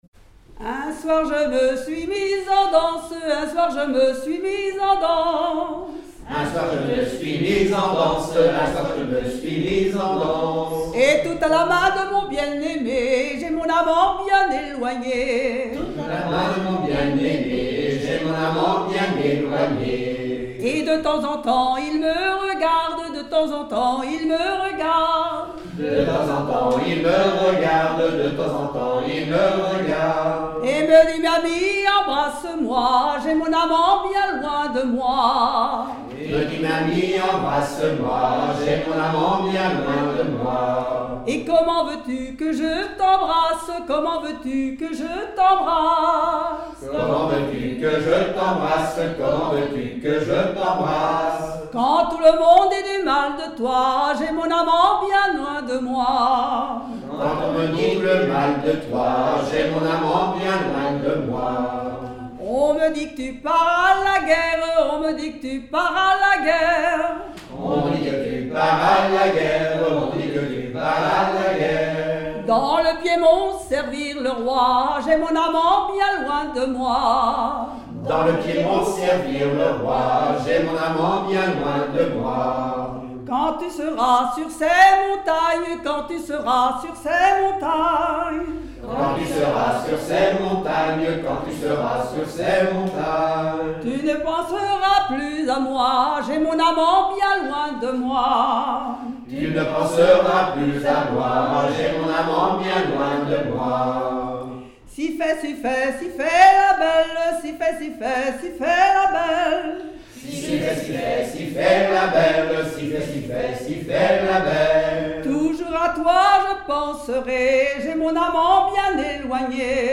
danse : ronde : rond de l'Île d'Yeu
Genre laisse
Pièce musicale inédite